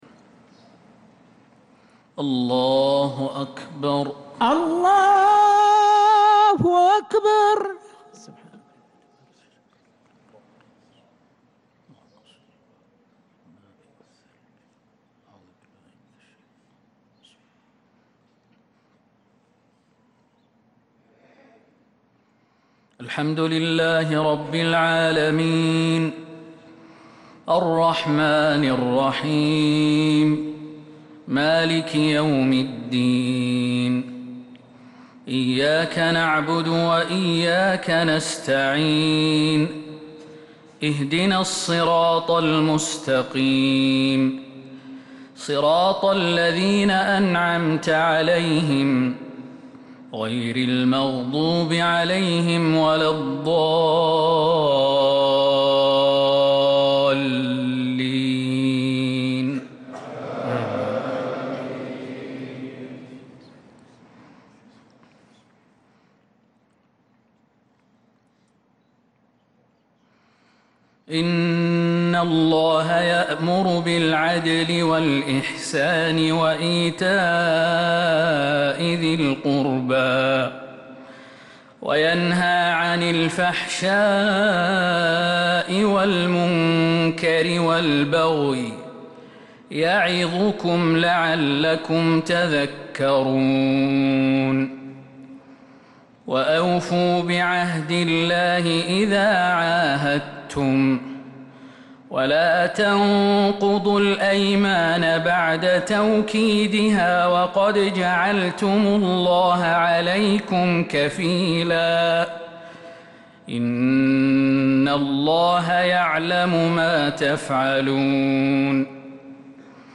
فجر الثلاثاء 13 محرم 1447هـ من سورة النحل 90-103 | Fajr prayer from Surat An-Nahl 8-7-2025 > 1447 🕌 > الفروض - تلاوات الحرمين